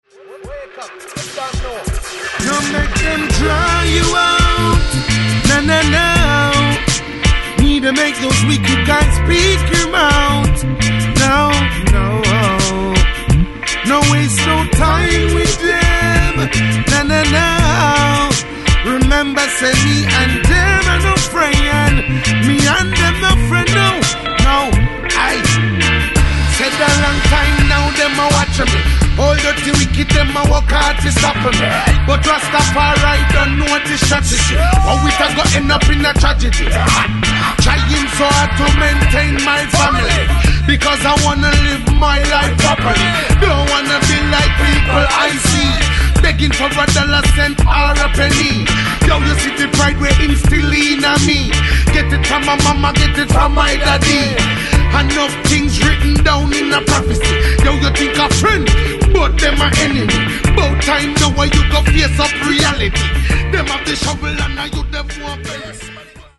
Catégorie : Reggae